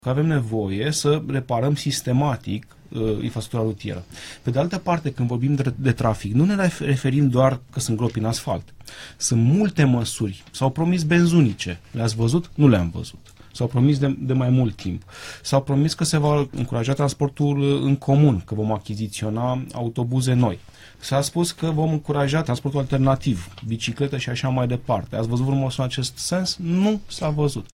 Invitat la Interviurile Europa FM, viceprimarul Aurelian Bădulescu (din partea PSD) a explicat că Primăria Generală pregătește abia acum caietele de sarcini pentru achiziții, dar producărorii ar fi anunțat că nu pot livra prea multe vehicule.